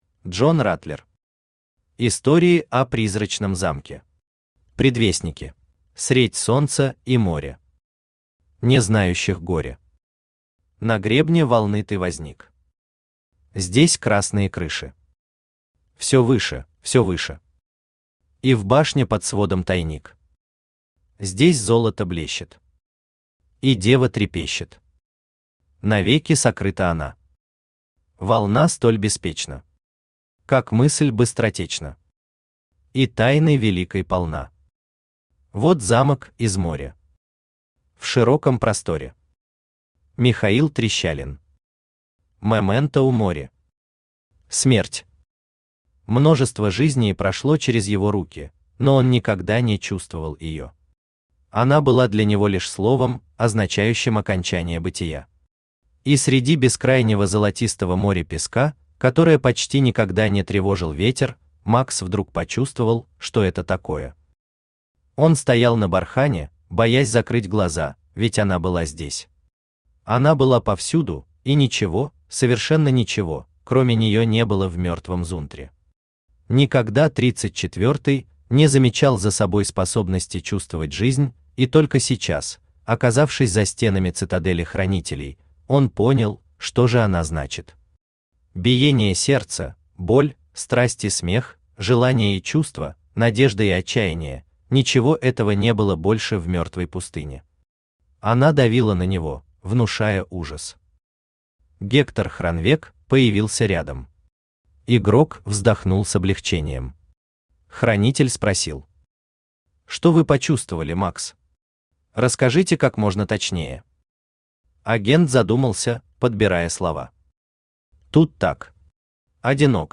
Аудиокнига Истории о Призрачном замке. Предвестники | Библиотека аудиокниг